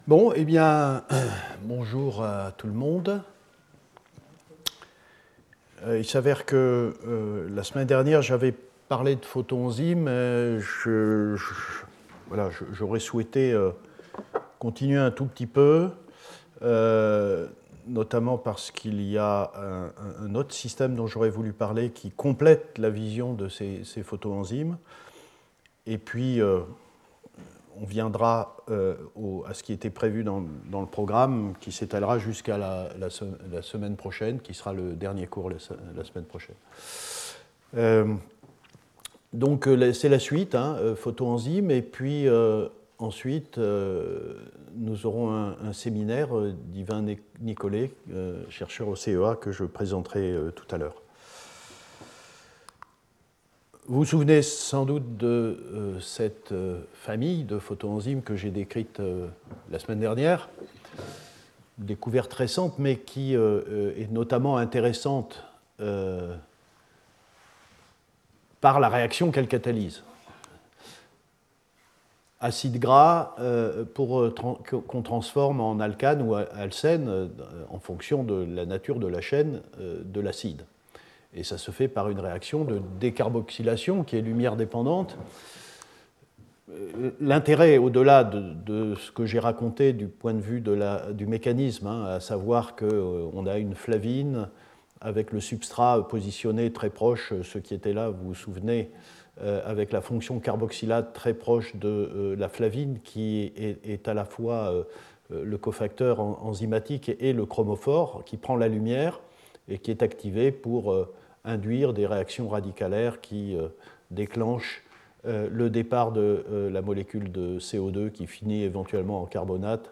The lectures 5 and 6 describe recent discoveries showing a much greater variety of these flavins, thanks in particular to the possibility of introducing substituents on the N5 position of the isoalloxazine ring.